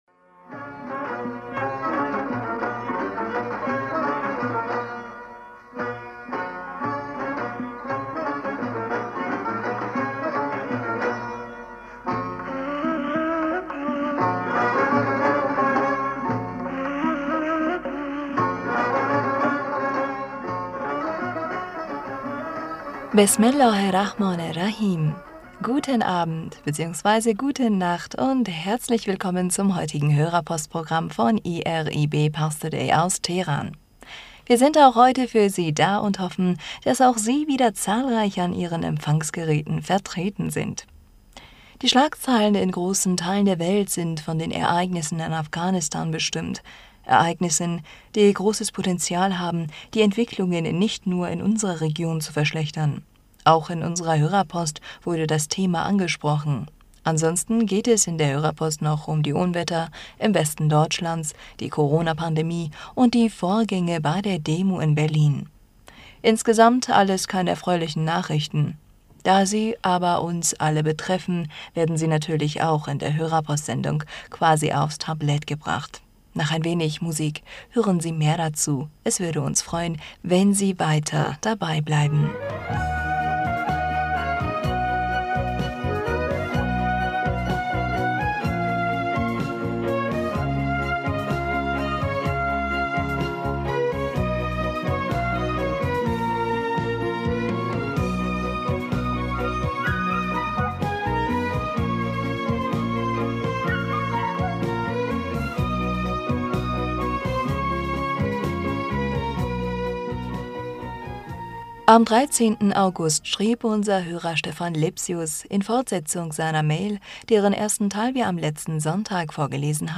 Hörerpostsendung am 22. August 2021 Bismillaher rahmaner rahim - Guten Abend beziehungsweise guten Nachmittag und Herzlich willkommen zum heutigen Hörerpo...